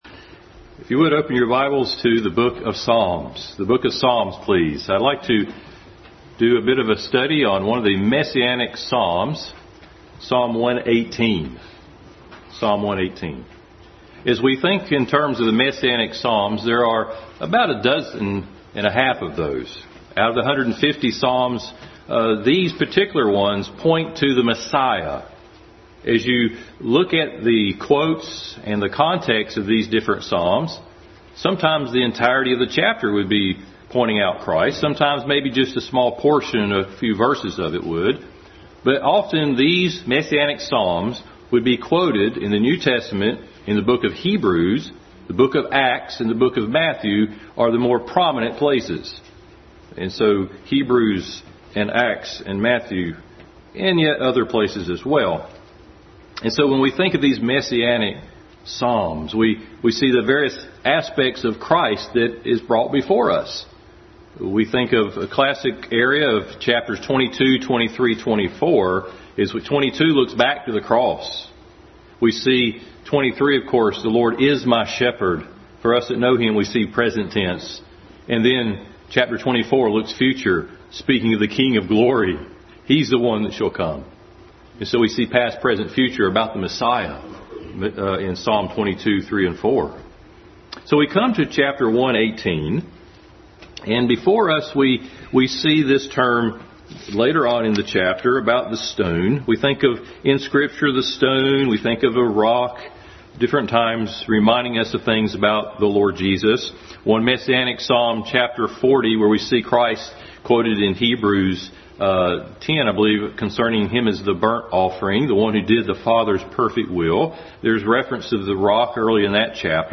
Psalm 118 Passage: Psalm 118, Matthew 21:42-46, Acts 4:8-12, Ephesians 1:19-23, Colossians 1:18, 1 Peter 2:4-10, Matthew 21:9 Service Type: Family Bible Hour Family Bible Hour message.